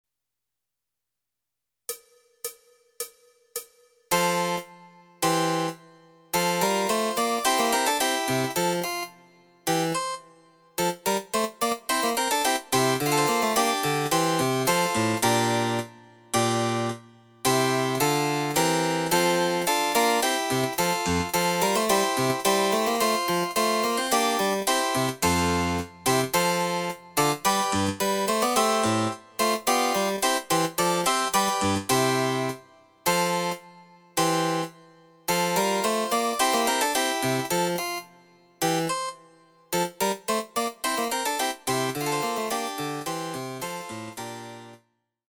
その他の伴奏